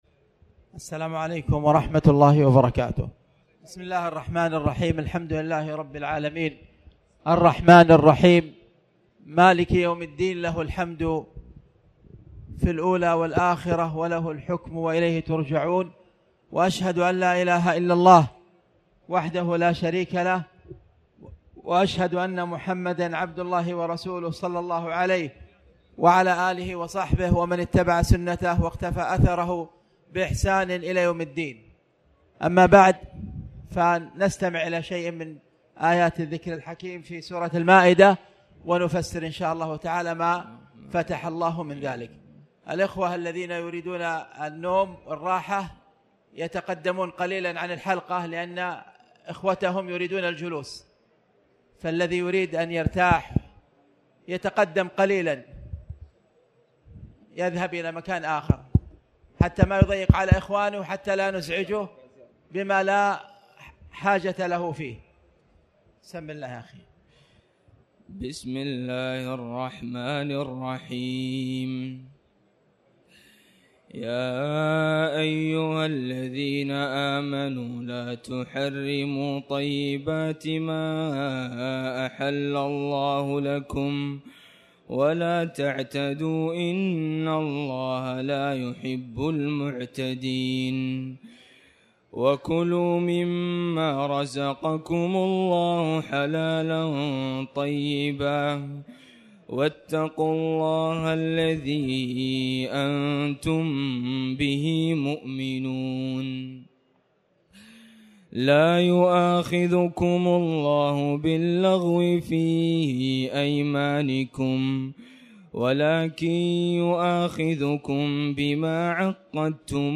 تاريخ النشر ٢٠ رمضان ١٤٣٩ هـ المكان: المسجد الحرام الشيخ